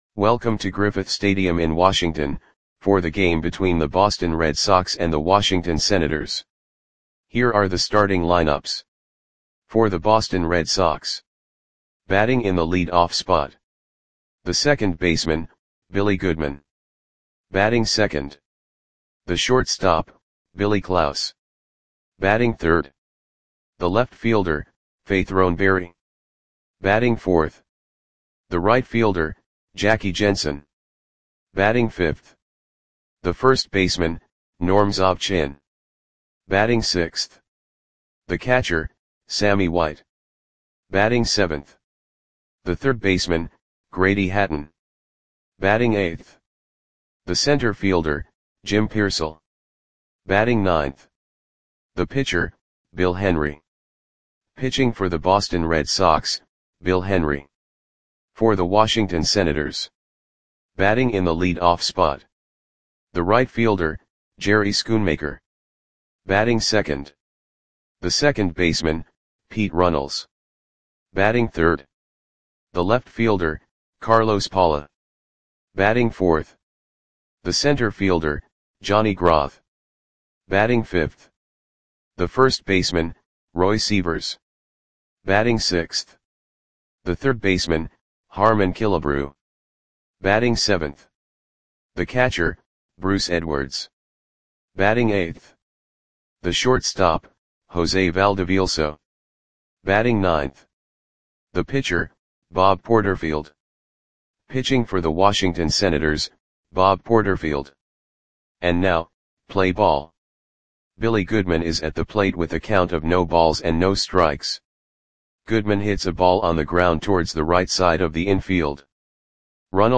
Audio Play-by-Play for Washington Senators on June 28, 1955
Click the button below to listen to the audio play-by-play.